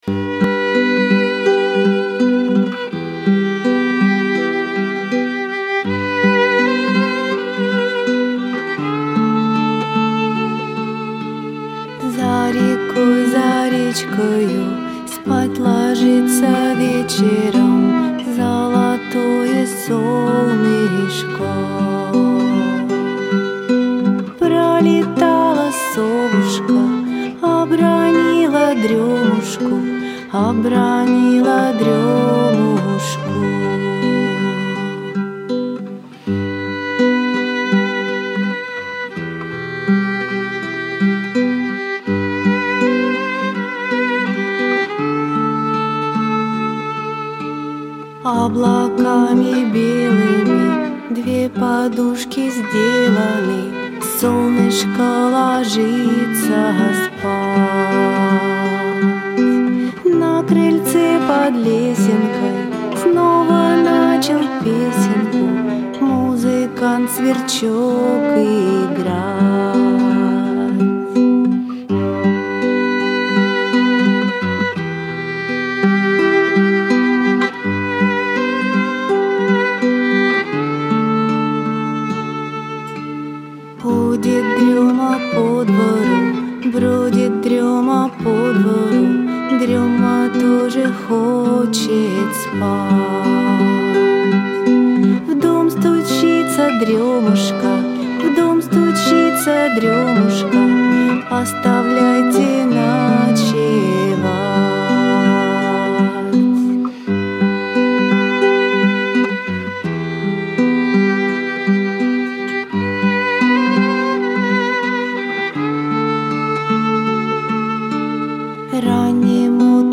Аудиокнига Волшебные колыбельные стихи | Библиотека аудиокниг